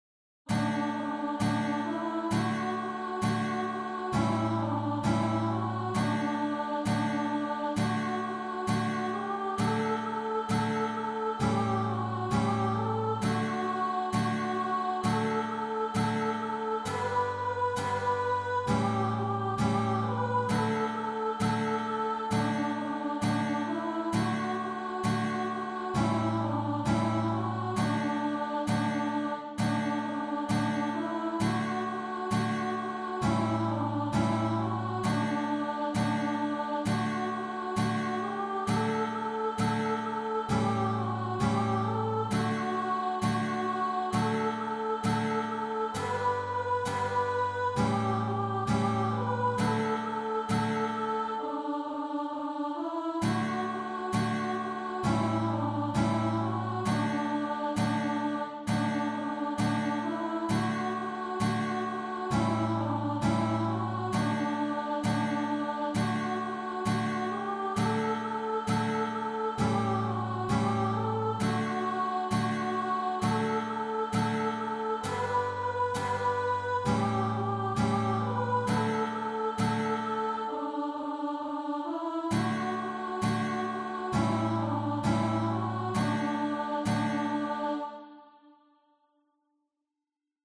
Begleitakkorde für Gitarre / Klavier und Gesang